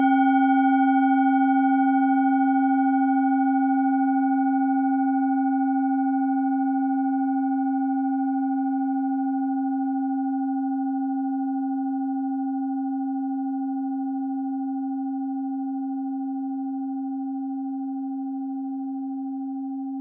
Klangschale TIBET Nr.3
Klangschale-Gewicht: 740g
Klangschale-Durchmesser: 16,2cm
Sie ist neu und ist gezielt nach altem 7-Metalle-Rezept in Handarbeit gezogen und gehämmert worden..
klangschale-tibet-3.wav